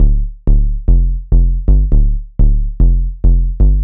cch_bass_solid_125_G.wav